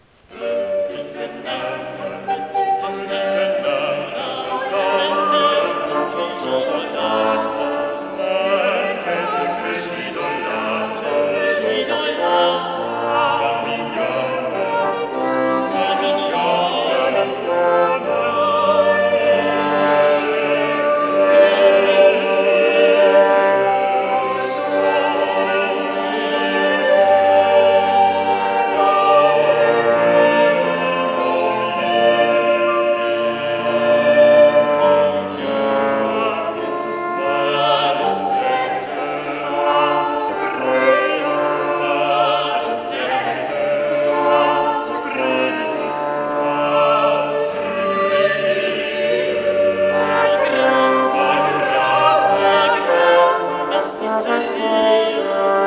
[ ill. nr. 34 partituur 'Petite Nymphe Folatre' uit C1473 ]"Petite nymphe folatre": chanson for eight voices from Chansons, odes et sonetz de Pierre Ronsard, Louvain-Antwerp, Phalèse-Bellère, 1576.
De Castro conceived "Petite nymphe folatre" as a lively rhythmical chanson for eight voices (a quite exceptional setting for a chanson). Choir groups in dialogue continuously exchange little flattering words. More than one third of the chanson is devoted to the musical elaboration of the last verse, a compositional 'strategy' inspired by the "mille baisers" ("thousand kisses") in the text.